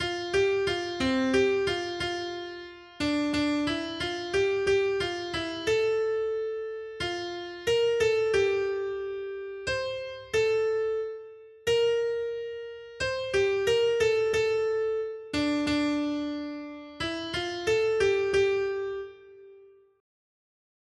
Noty Štítky, zpěvníky ol402.pdf responsoriální žalm Žaltář (Olejník) 402 Skrýt akordy R: Hospodine, Pane náš, jak podivuhodné je tvé jméno po celé zemi! 1.